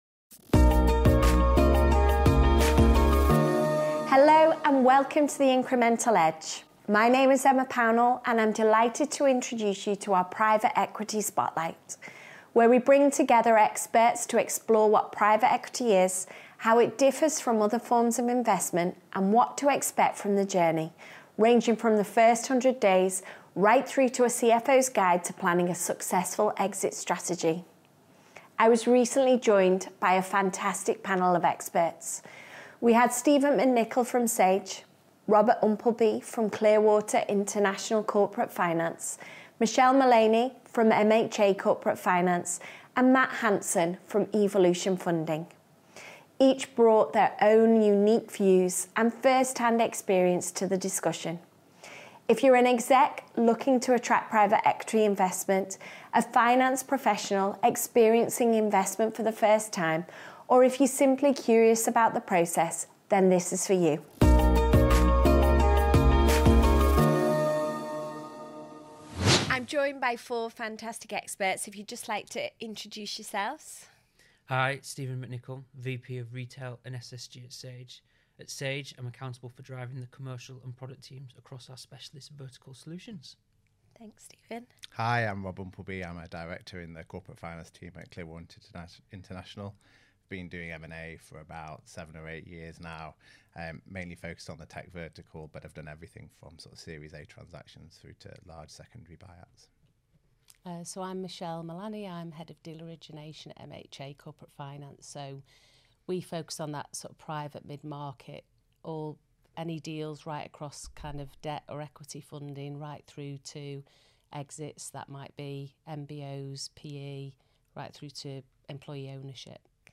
Private Equity (PE) explained: Join our expert panel as they share essential insights for CFOs navigating the PE investment journey.